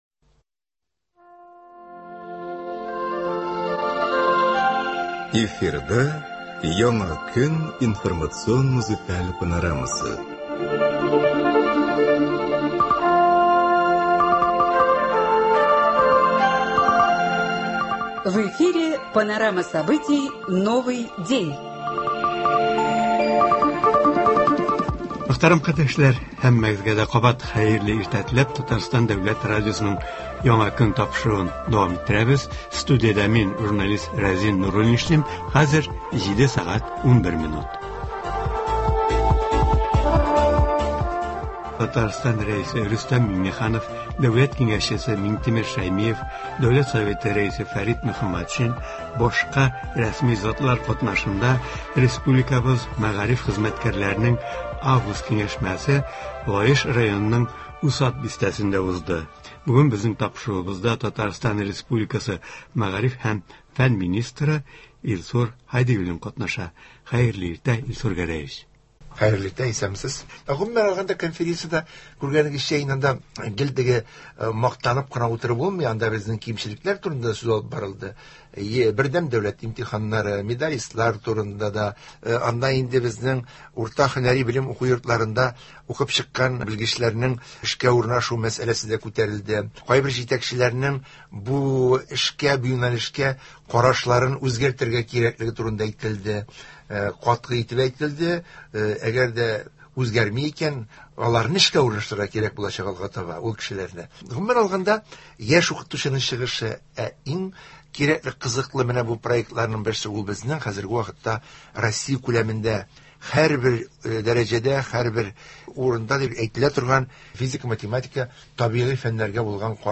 Бүген республикабыз мәгариф системасында яңа уку елы башлану уңаеннан тантаналы чаралар үткәрелә, алга бурычлар билгеләнелә. Болар хакында Татарстан республикасы мәгариф һәм фән министры Илсур Гәрәй улы Һадиуллин белән әңгәмә барышында сүз кузгатыла, мәгариф хезмәткәрләренең август киңәшмәсе йомгакларына бәя бирелә.